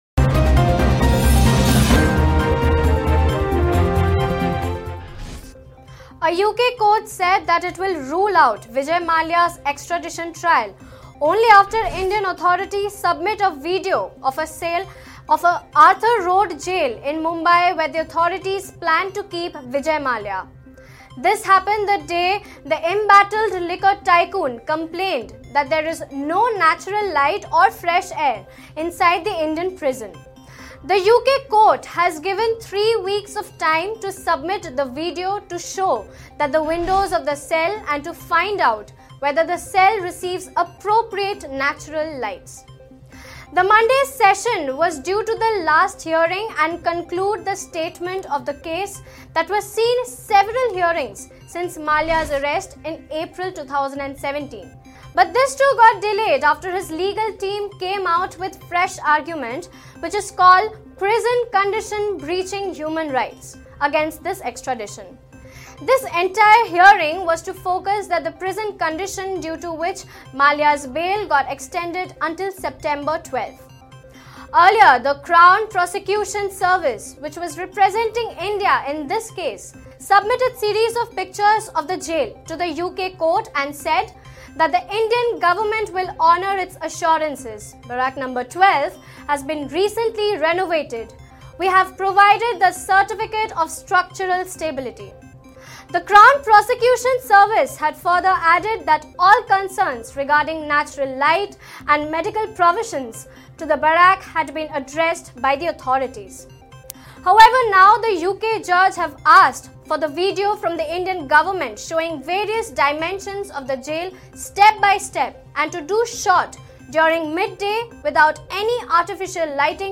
News Report / Vijay Mallya demands clean jail ahead of deportation to India